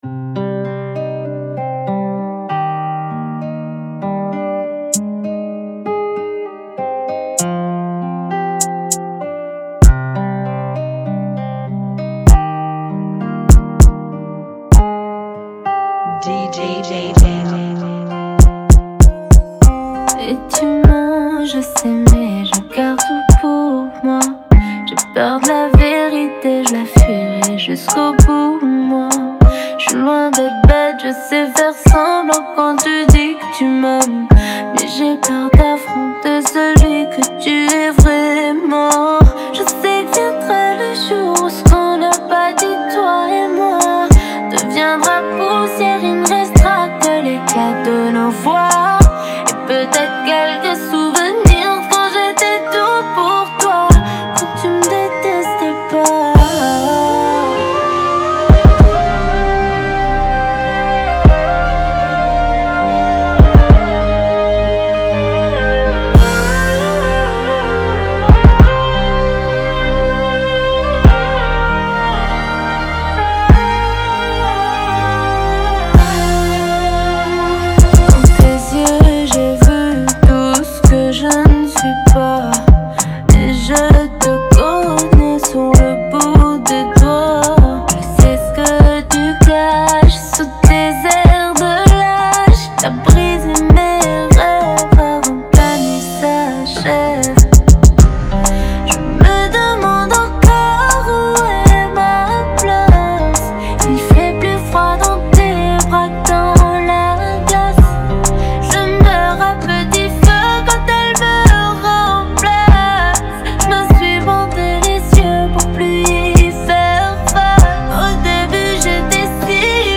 (98 BPM)
Genre: Kizomba Remix